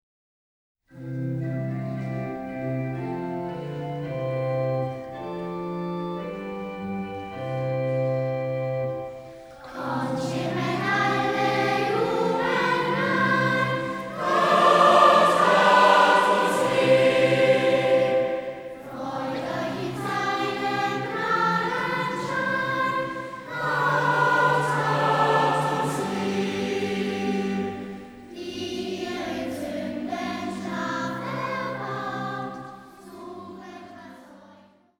Kinderchor, gemischter Chor, Orgel